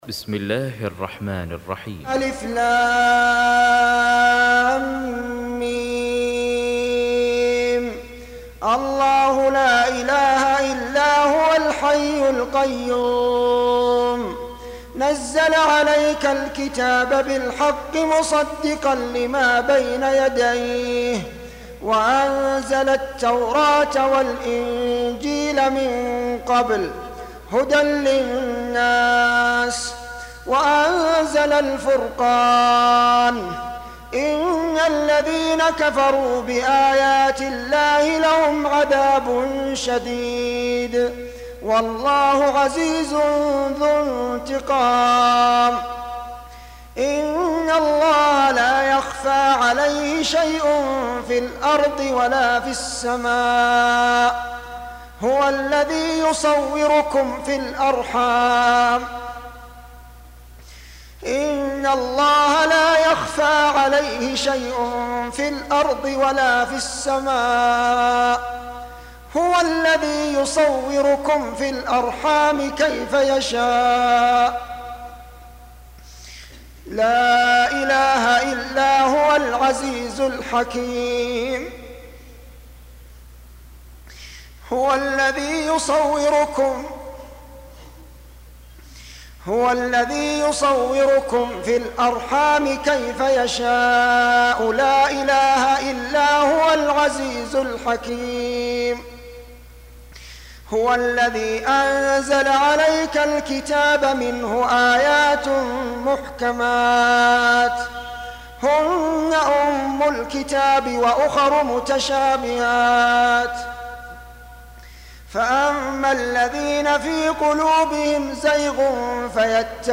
Surah Repeating تكرار السورة Download Surah حمّل السورة Reciting Murattalah Audio for 3. Surah �l-'Imr�n سورة آل عمران N.B *Surah Includes Al-Basmalah Reciters Sequents تتابع التلاوات Reciters Repeats تكرار التلاوات